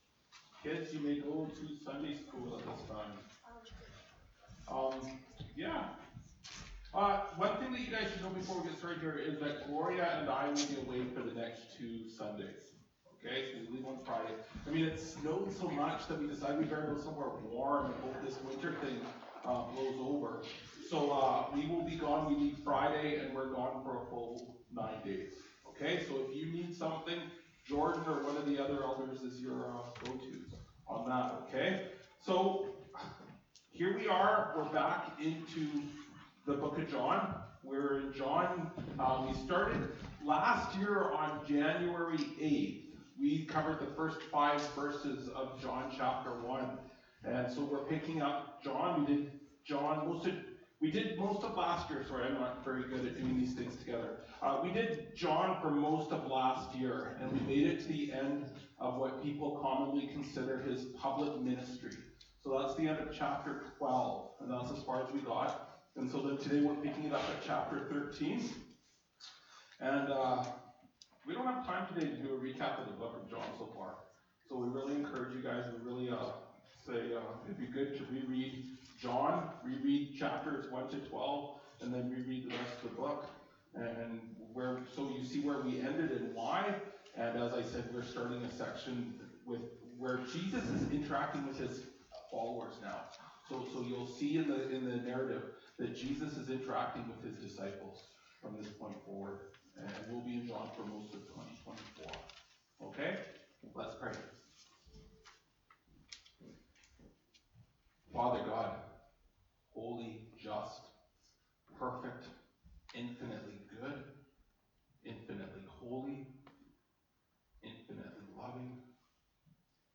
Jan 07, 2024 Dirty Feet (John 13:1-20) MP3 SUBSCRIBE on iTunes(Podcast) Notes Discussion Sermons in this Series This sermon was recorded in Salmon Arm and preached in both Salmon Arm and Enderby.